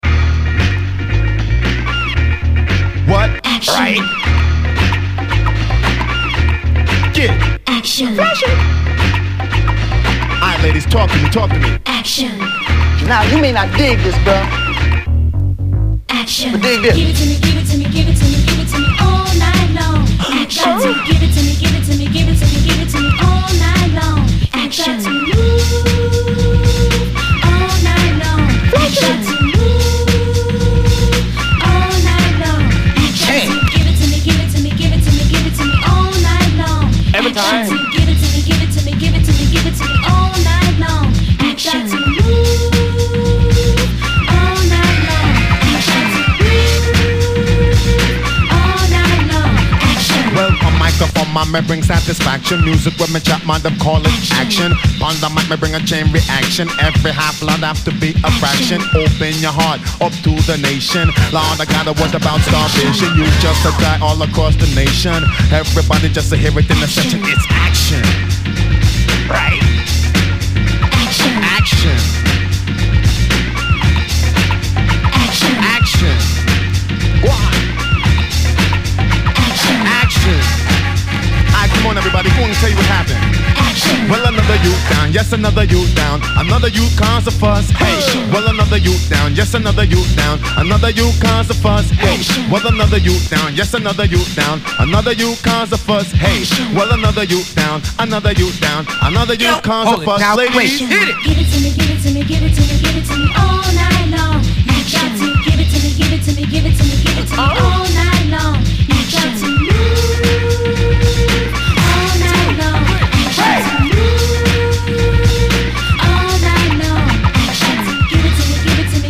REGGAE, HIPHOP